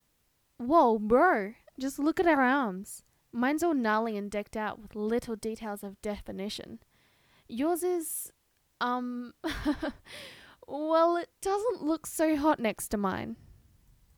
A very talented young lady took it upon herself to record (almost) every single line of dialogue Sarah speaks in the story.